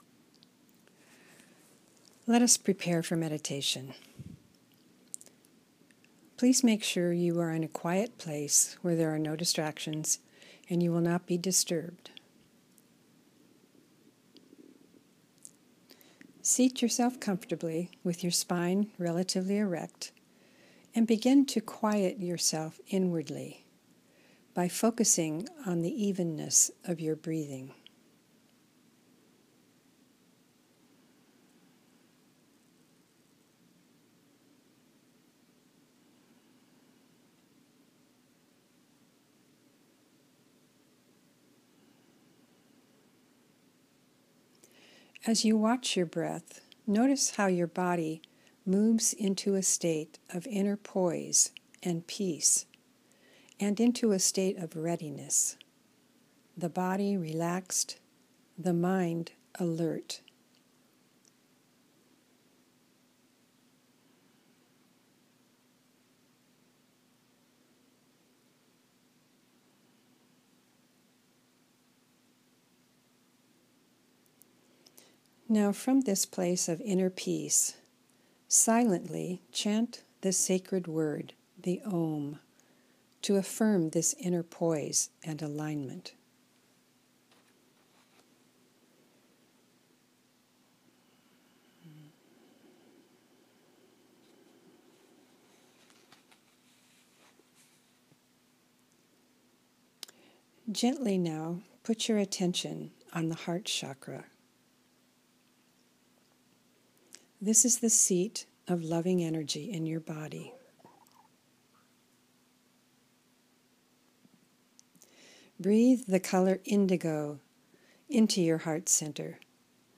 Have the Full Moon Ceremony and Guided Meditation sent to you each month.